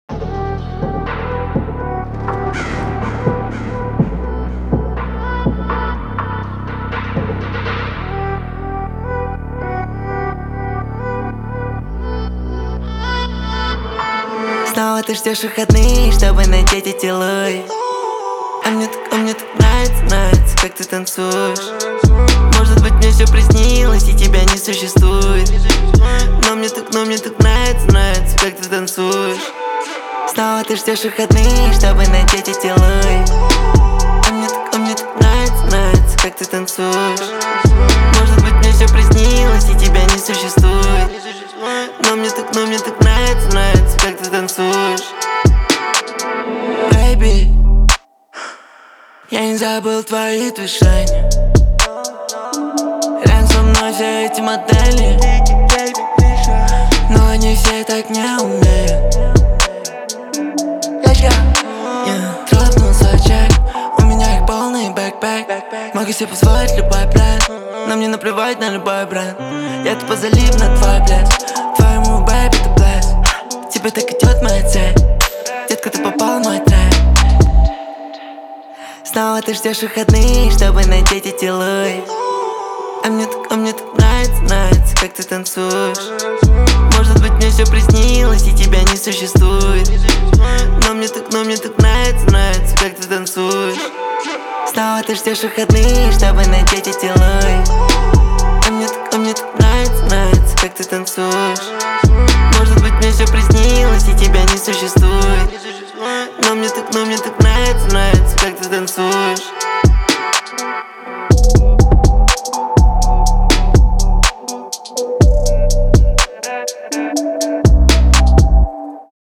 • Жанр: Русская музыка